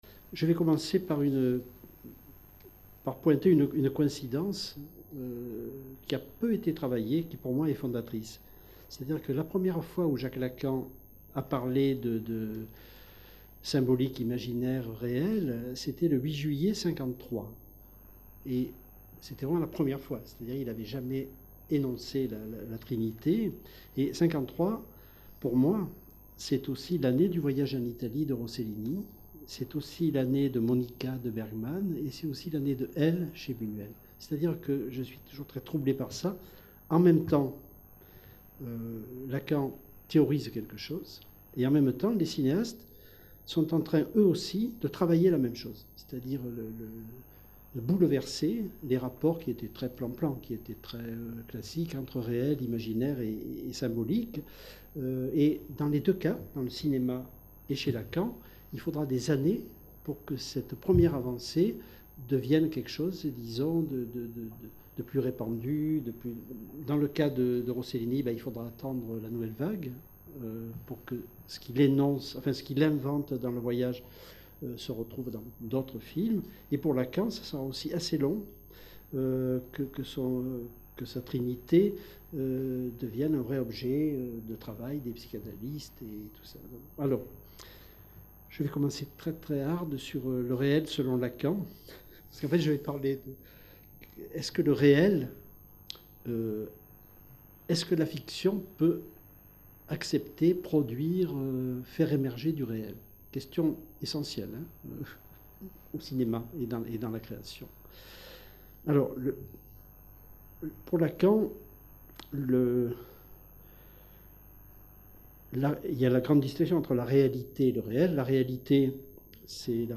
Cette communication a été filmée dans le cadre du colloque Aux frontière de la fiction : théories, pratiques et création organisé par l'équipe du LASLAR à la Maison de la Recherche en Sciences Humaines de l'Université de Caen Normandie.